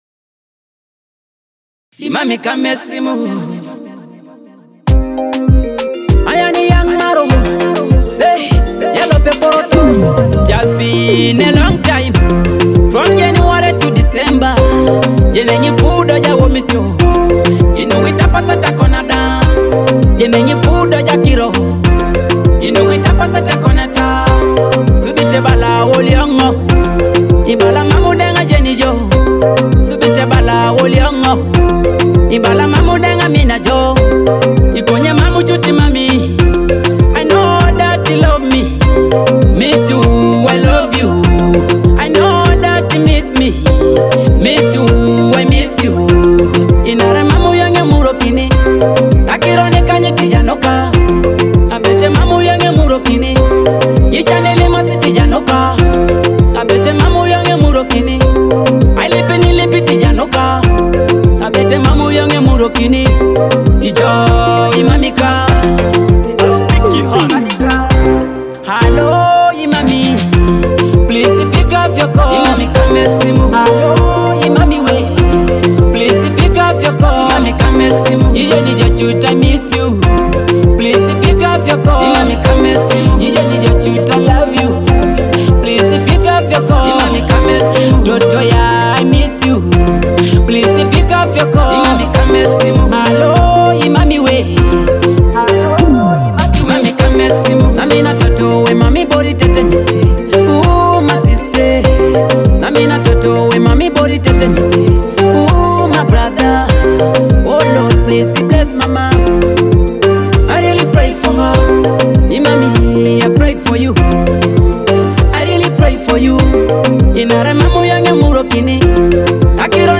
Blending emotive melodies with modern beats